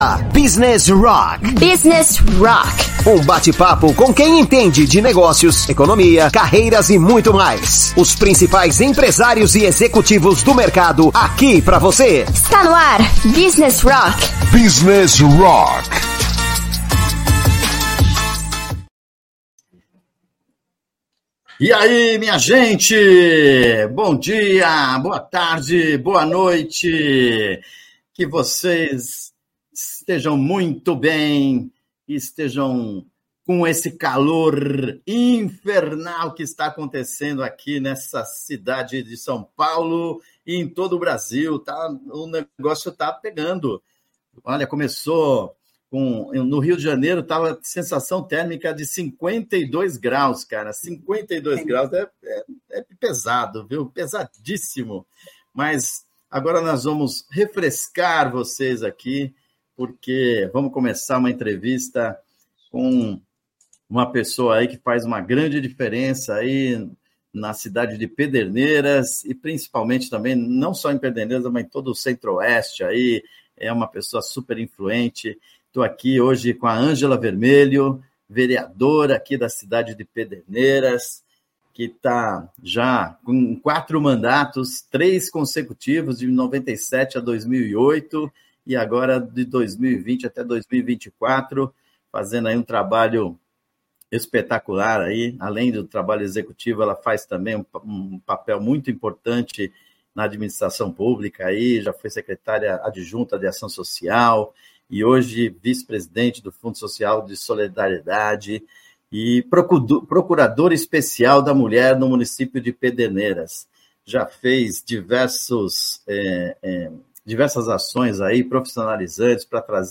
Hoje, o Business Rock entrevista Angela Vermelho sobre compromisso e justiça social. A vereadora de Pederneiras (SP) também atuou na administração pública como Secretária Adjunta de Ação Social, se dedicou à solidariedade e assistência social e se destacou como Vice-Presidente do Fundo Social de Solidariedade, onde trabalhou com pessoas em situação de vulnerabilidade social.